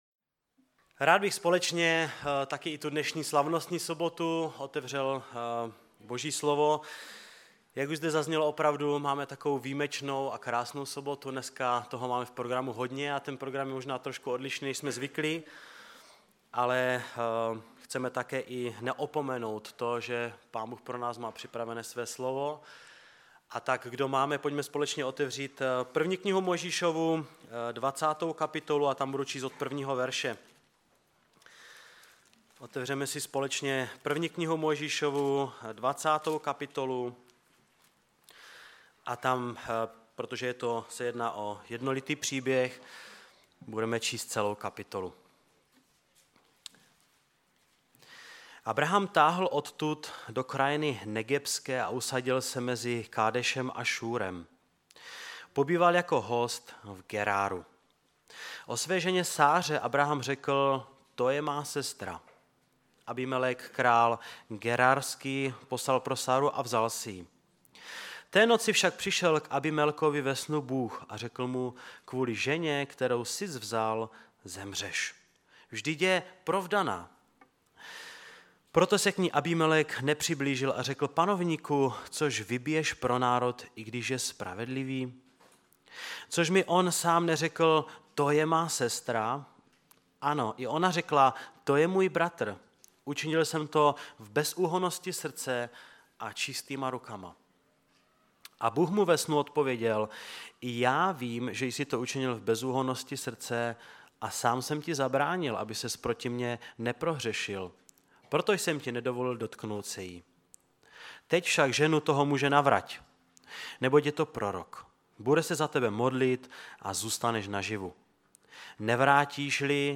ve sboře Ostrava-Radvanice, při příležitosti Památky Večeře Páně.
Kázání